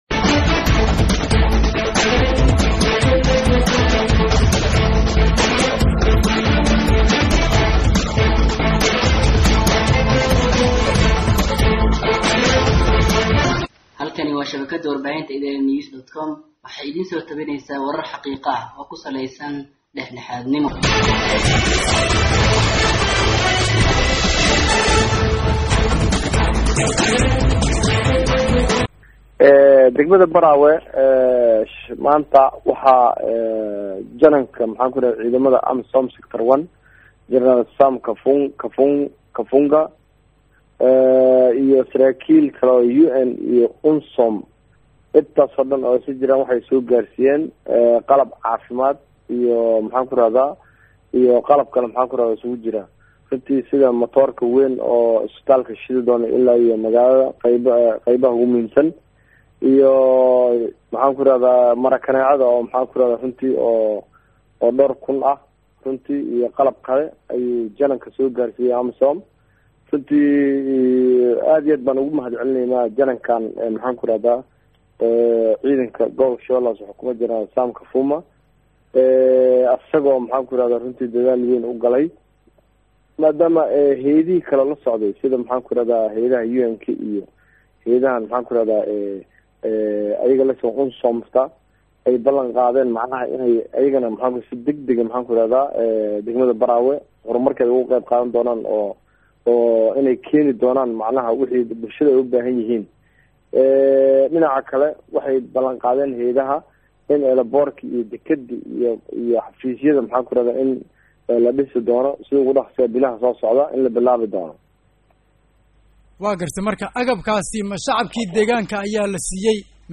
Dhageyso Wareysi:Guddoomiye Siidii Oo Fariin Kulul U Diray Madaxweyne Xasan Kana Hadlay Isbaarooyinka G/Sh/Hoose